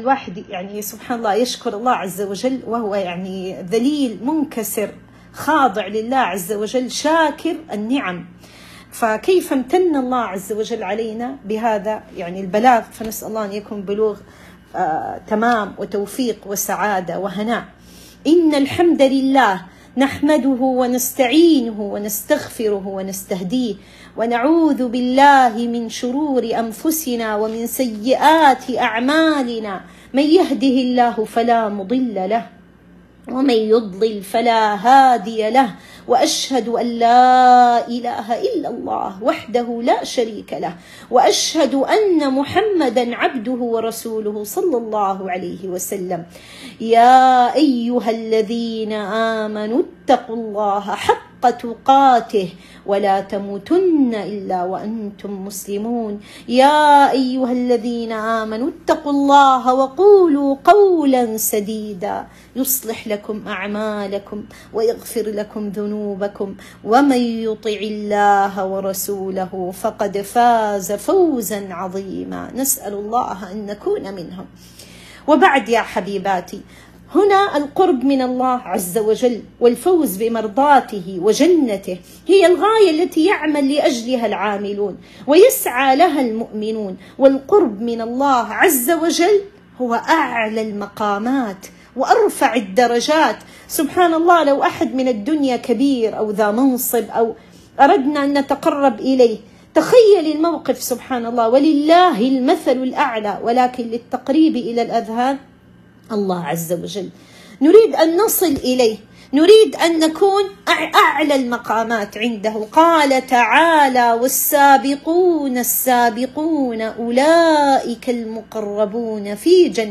* *لقاء* 🎙 🎙 * 🕋 (أقبلت العشر تعدو .. فاستعدوا )